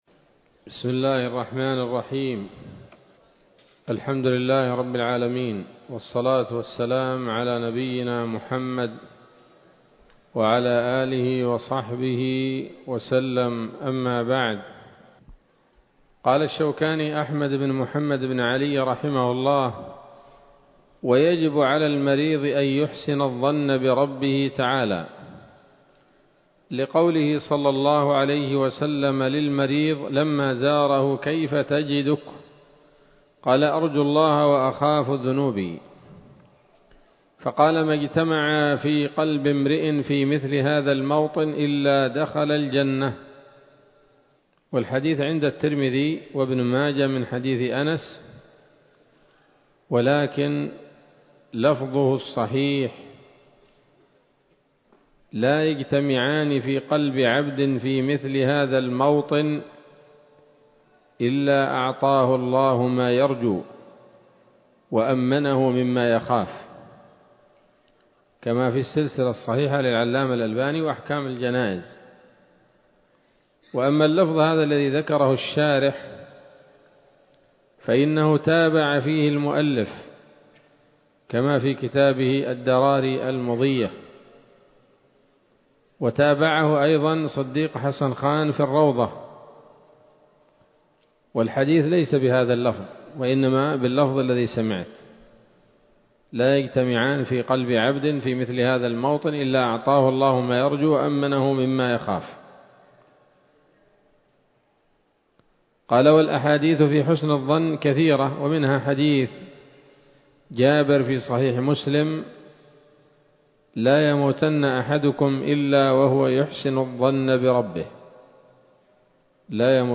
الدرس الثاني من كتاب الجنائز من السموط الذهبية الحاوية للدرر البهية